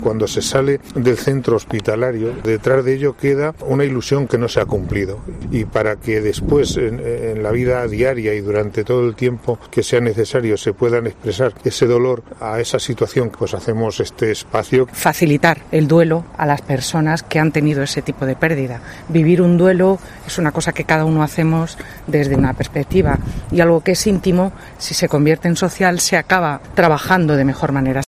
alcalde de Manzanares